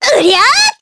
Cleo-Vox_Attack4_jp.wav